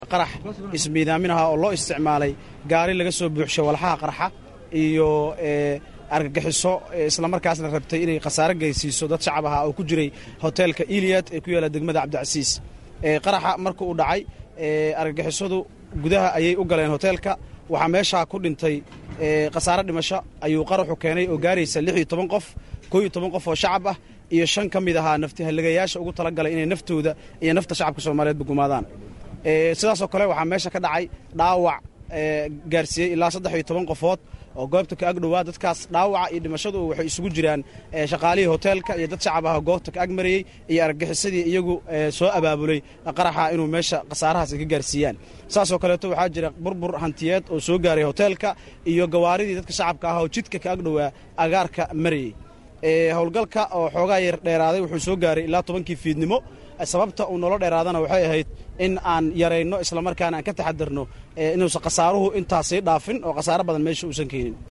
warbaahinta kula hadlay Albaabka hore ee Hotelka